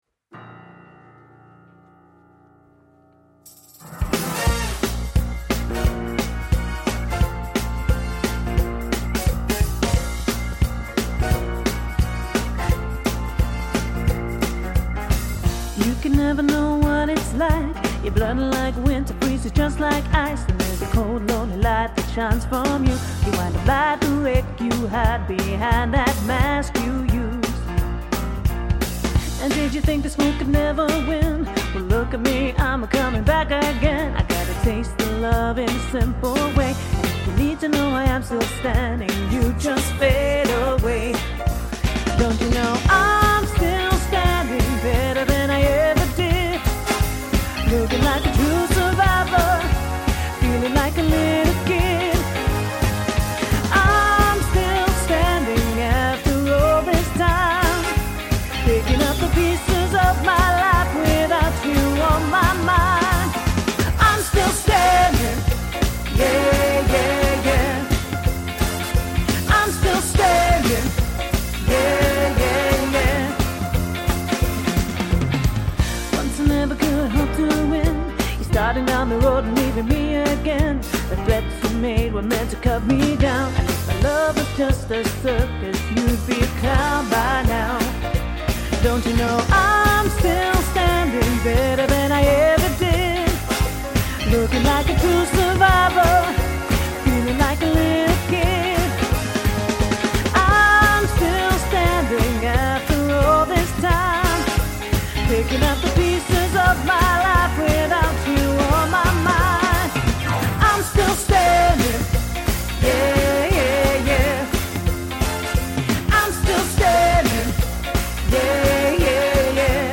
Professional singers... masquerading as waiters!